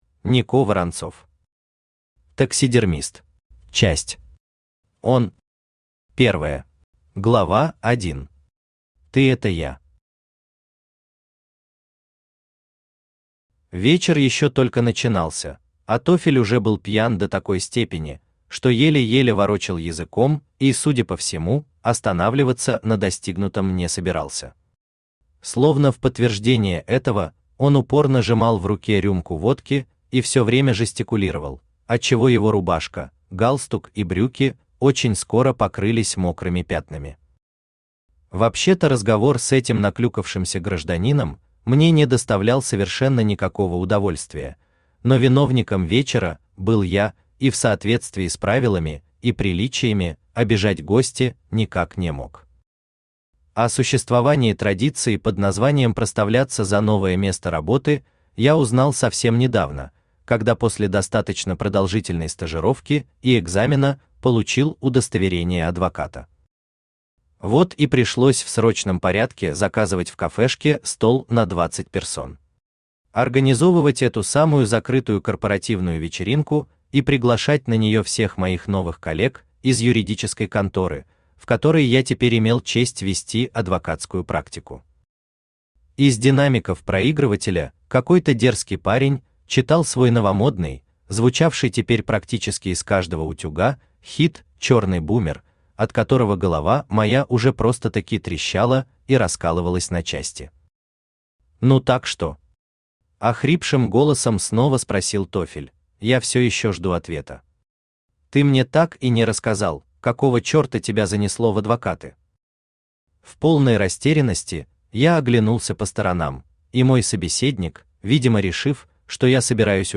Aудиокнига Таксидермист Автор Нико Воронцов Читает аудиокнигу Авточтец ЛитРес.